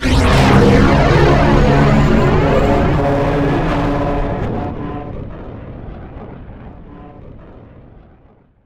takeoff_2.wav